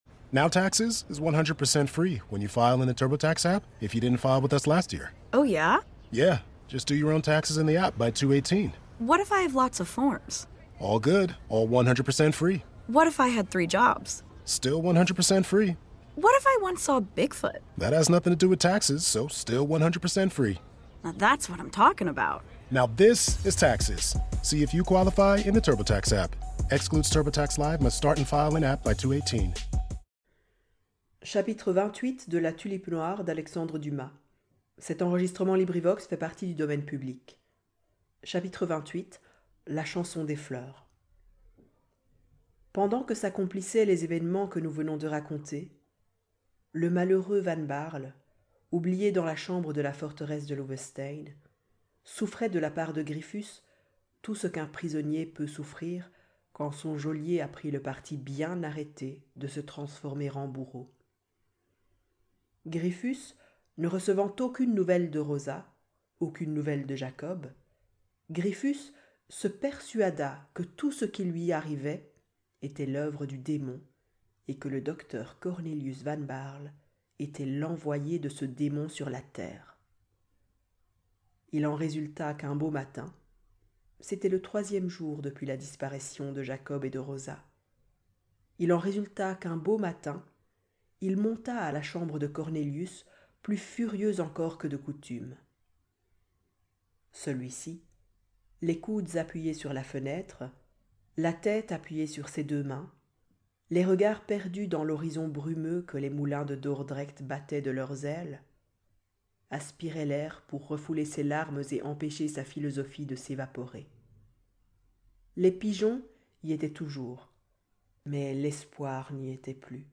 Livres Audio